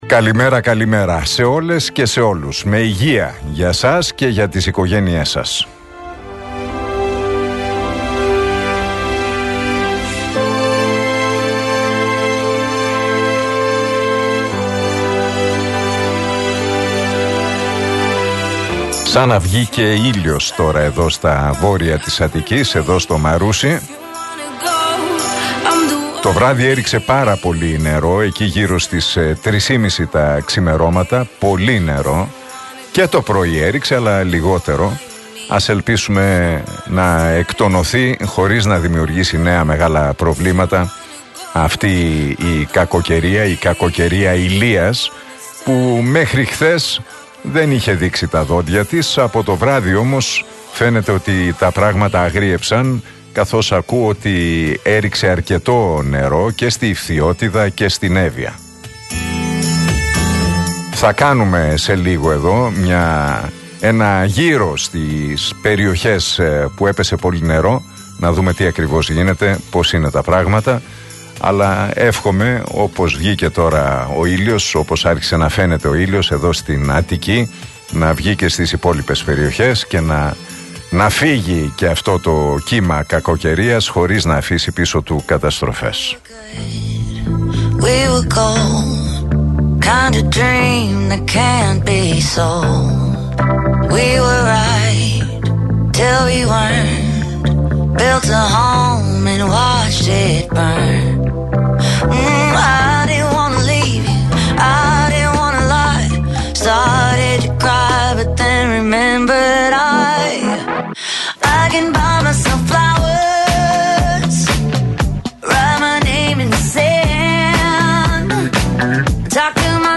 Ακούστε το σχόλιο του Νίκου Χατζηνικολάου στον RealFm 97,8, την Τετάρτη 27 Σεπτεμβρίου 2023.